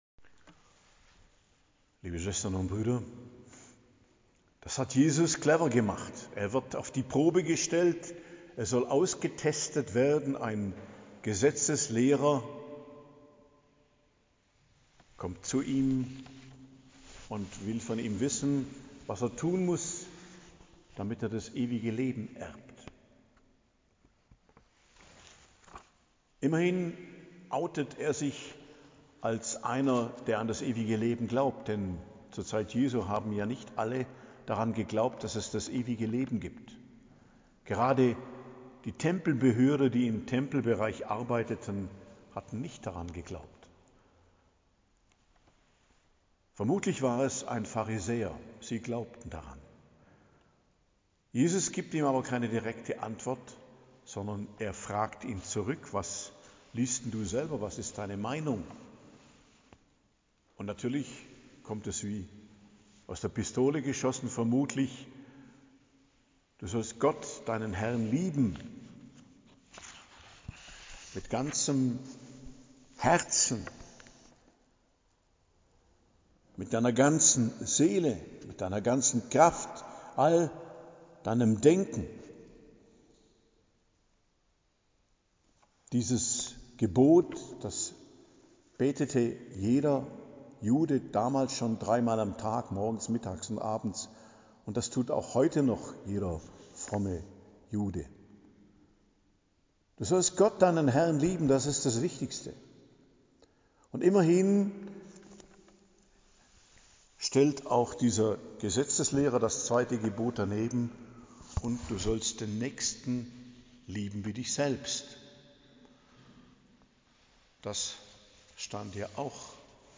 Predigt zum 15. Sonntag i.J., 13.07.2025 ~ Geistliches Zentrum Kloster Heiligkreuztal Podcast